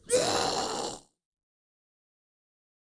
normal zombie die 6.mp3